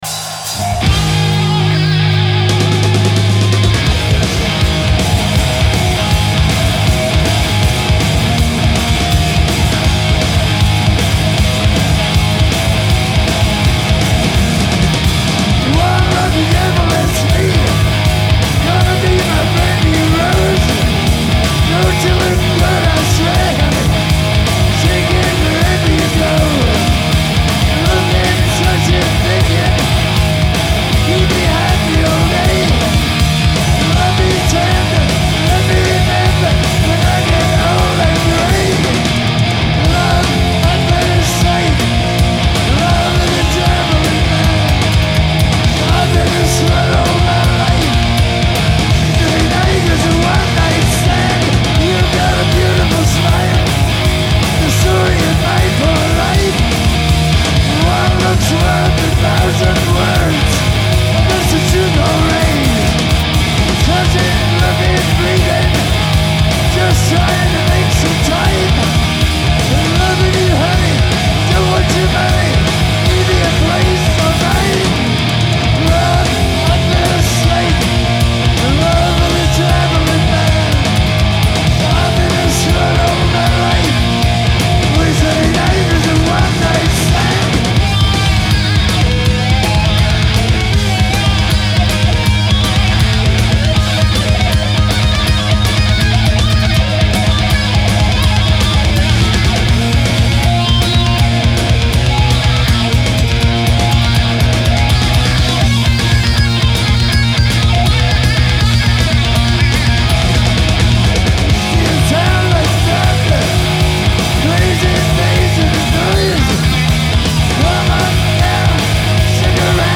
Hi-Res Stereo
Genre : Rock